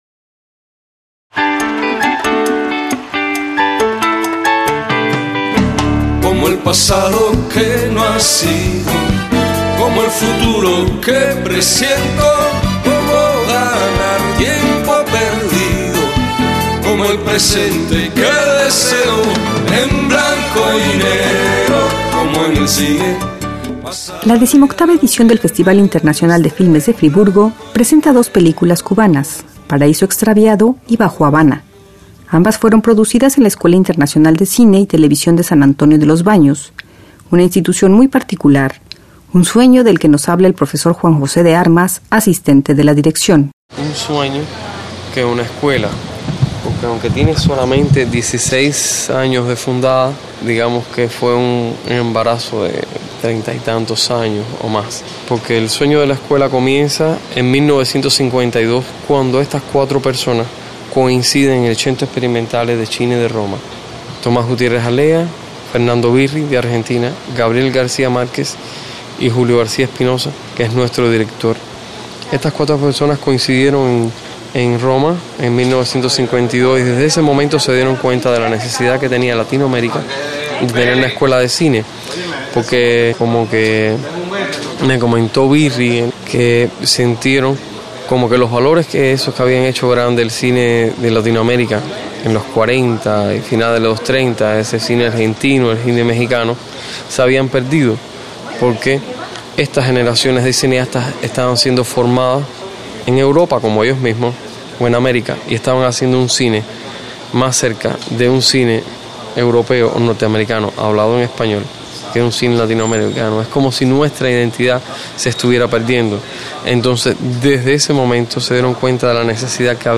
swissinfo presenta los testimonios de algunos protagonistas de ese vínculo tan especial.